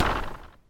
removing buildings
Recycle.mp3